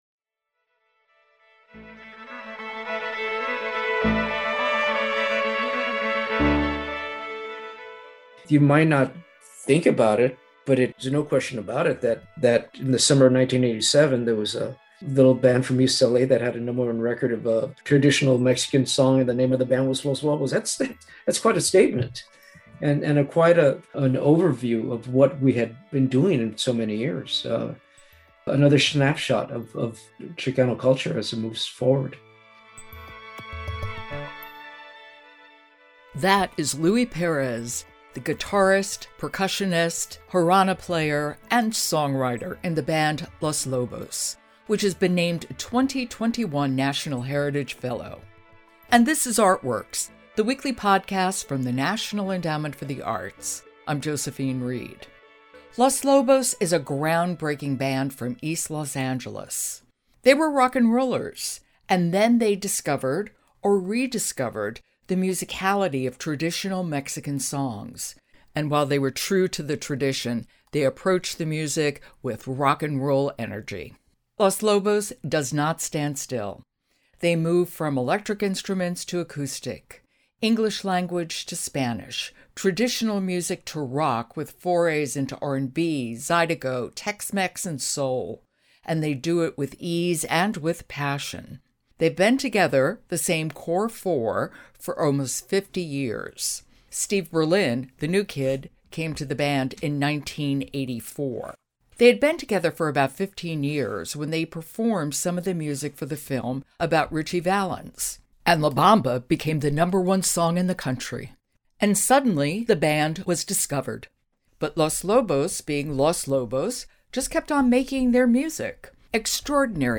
Louie Pérez of the band Los Lobos, 2021 National Heritage Fellows, talks about keeping musical and cultural traditions alive and evolving.